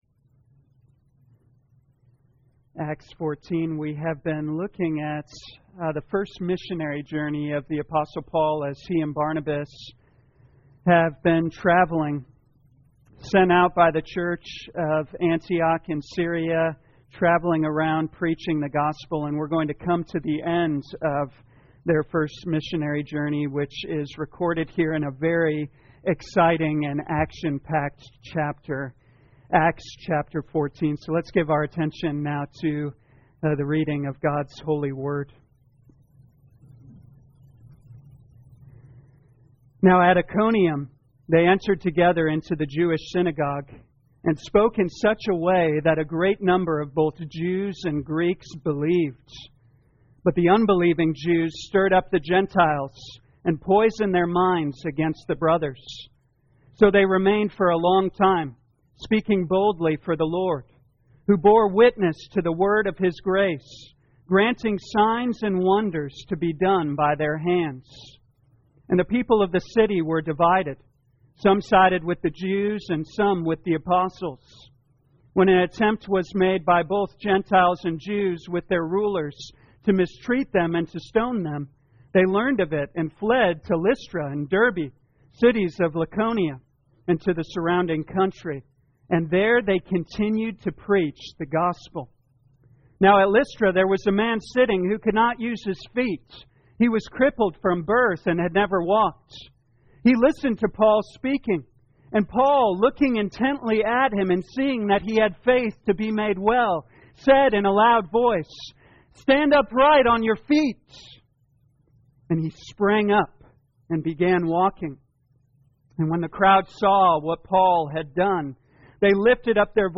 2022 Acts Morning Service Download